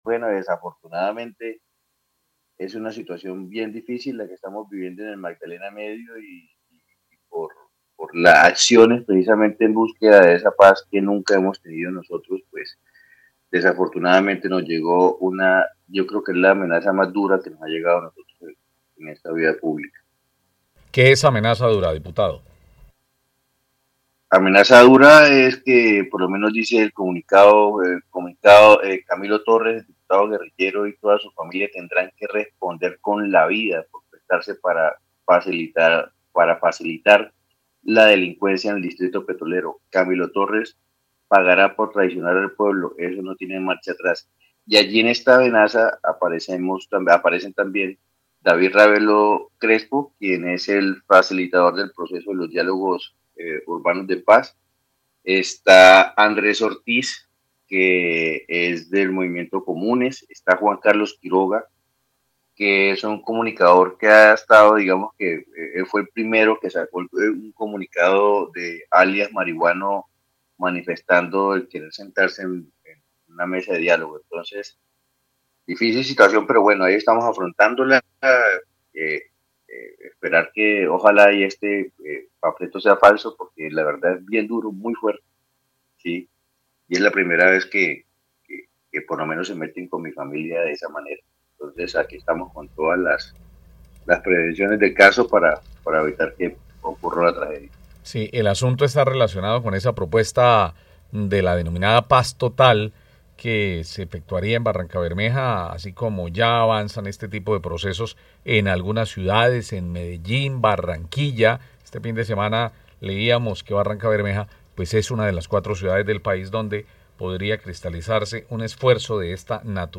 Según lo relatado por Torres Prada en entrevista en Caracol Radio, el mensaje califica al diputado como “guerrillero” y lo acusa de “facilitar la delincuencia en Barrancabermeja”, asegurando que tanto él como su familia “tendrán que responder con la vida”.